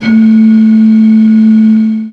55bw-flt12-a3.aif